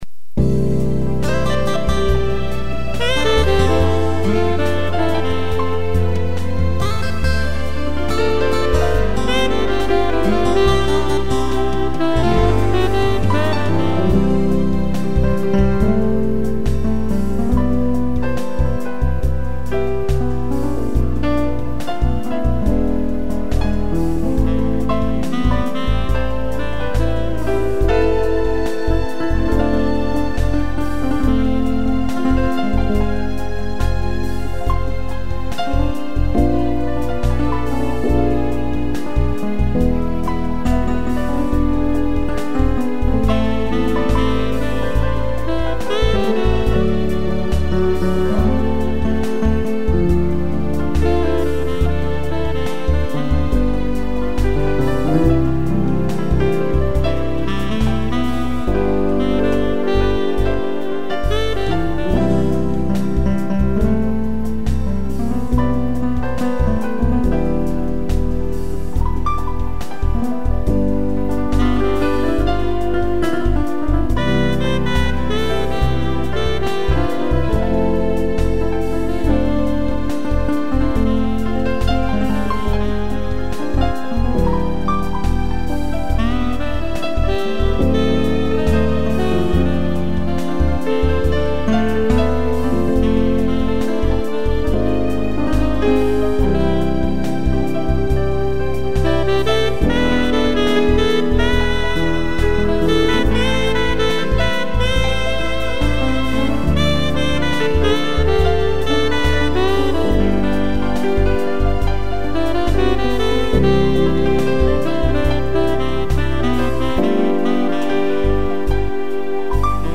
piano, sax e violino
instrumental